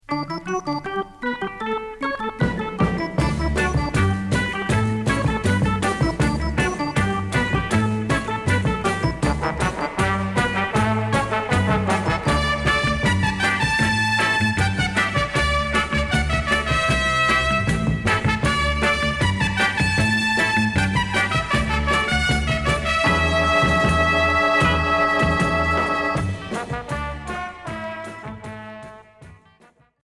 Jerk